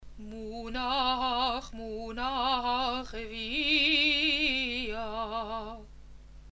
The sound of the Haftarot is the sound of forgiveness, even if the texts may be admonitions to call us to our tasks.
Revia-Clause (Haftarah)